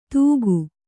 ♪ tūgu